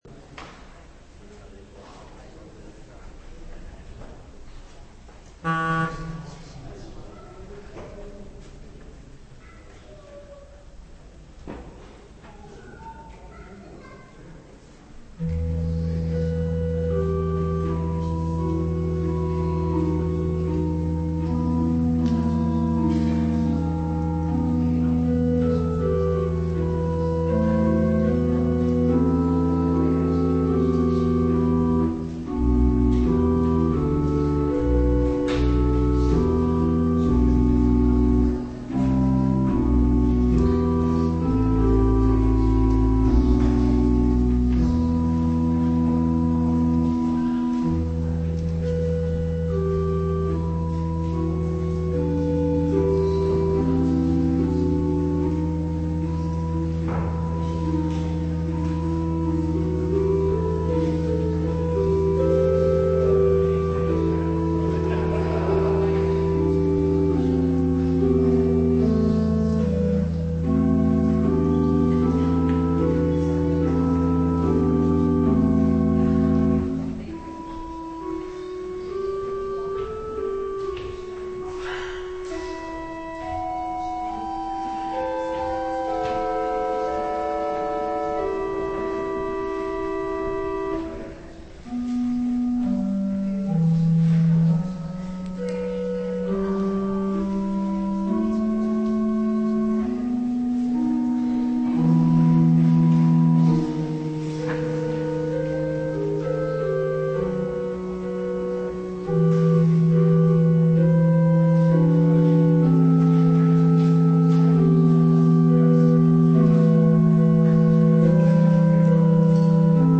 Meditatie over Jesaja 53:7-9 tijdens de derde vesper in de Stille Week op 16 april 2025 - Pauluskerk Gouda